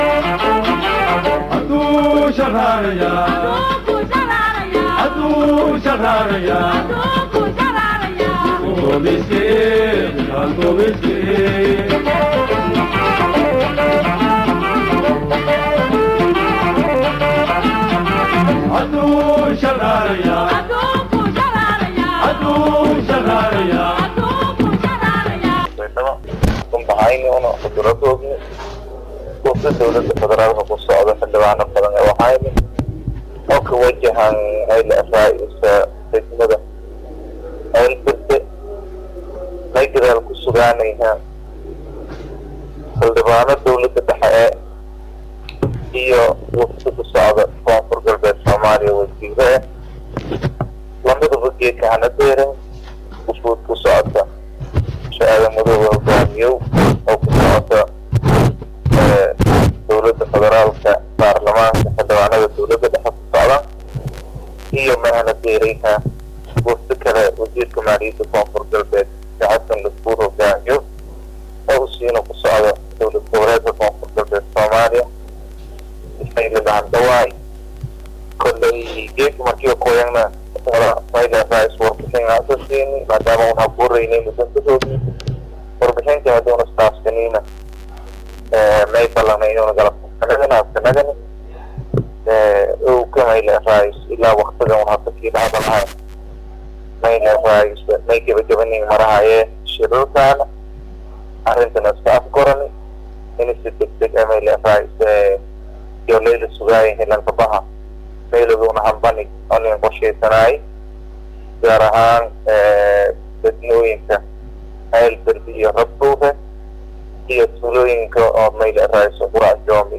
Xildhibaan Cumar Cali Balash oo ka mid ah Xildhibaanada HalkaasI Gaarey oo wareysi  Gaar ah siyey Shabakadda Warbaahinta Idale News Online,ayaa sheegey in laga wada shiray Qaabkii  loo tagi lahaa iyadoona uu sheegey in Shacabka Degmada Ceelberde ay soo dalbanayaan Imaatinka Wasiiradda iyo Xidhibaanadda, waxa uu meesha ka saarey in aysan Jirin Maamul halkaasi ka dhisan.